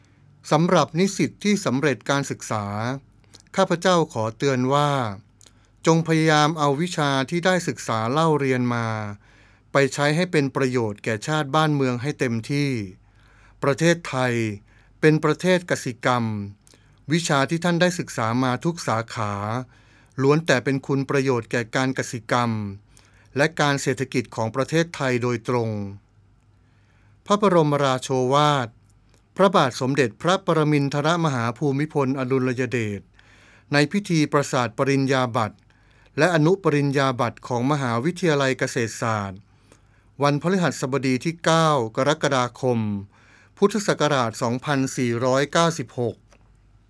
พระบรมราโชวาท
ในพิธีประสาทปริญญาบัตรและอนุปริญญาบัตร ของมหาวิทยาลัยเกษตรศาสตร์